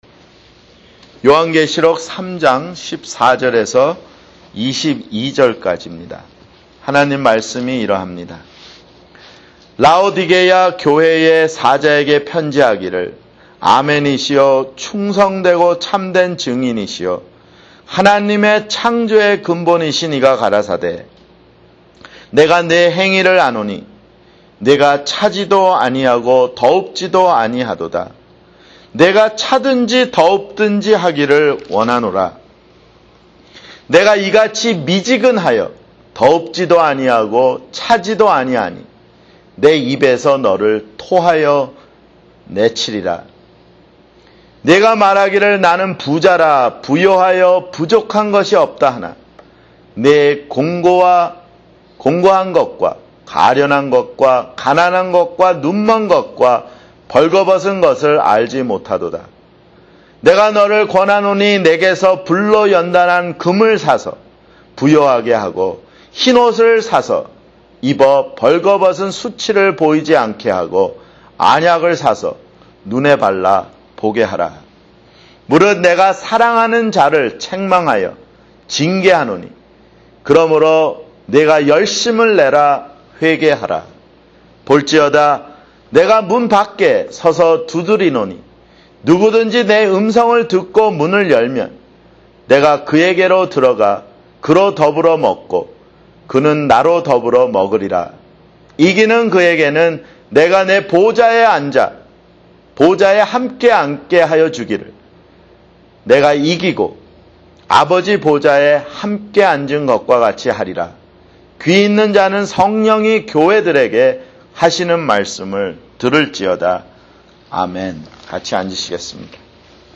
[주일설교] 요한계시록 (18)